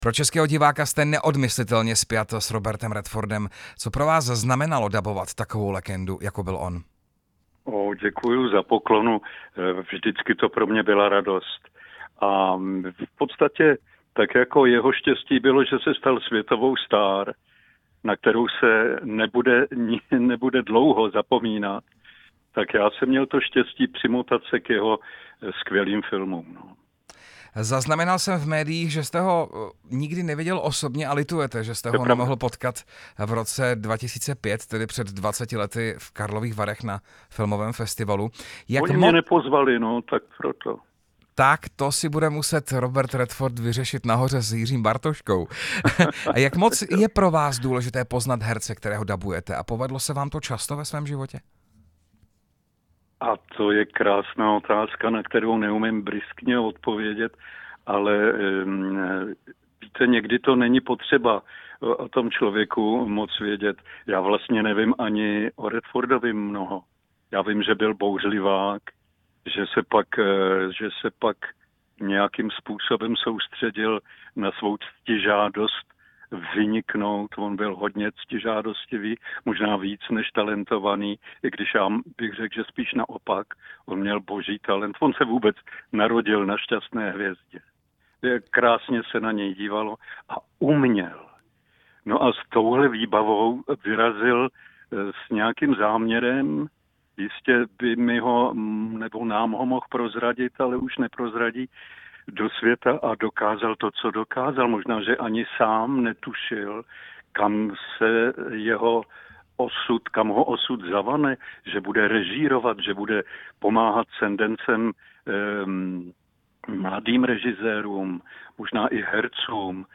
Rozhovor s hercem a dabérem Pavlem Soukupem